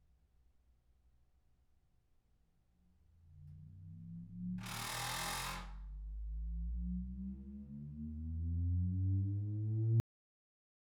vibe-issue.wav